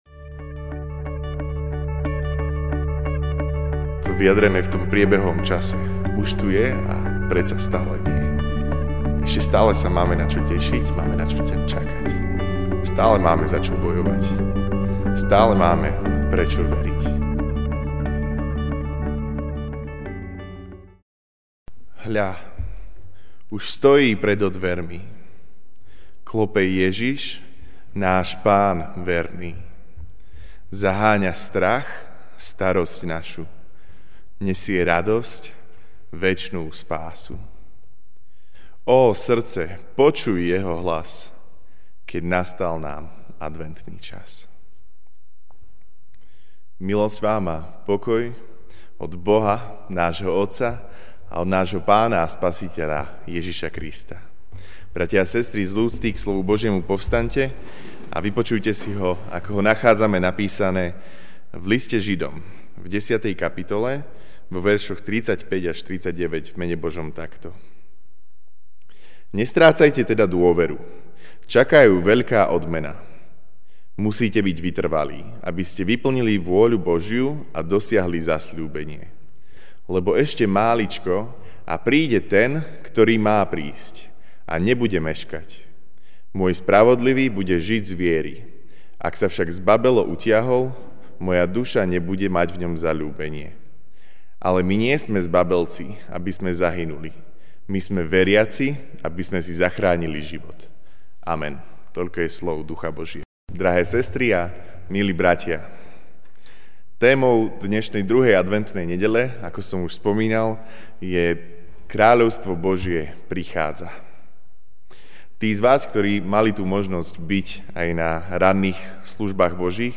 Večerná kázeň: Už a ešte nie (Žid 10, 35-39) 'Nestrácajte teda dôveru: čaká ju veľká odmena.